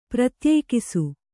♪ pratyēkisu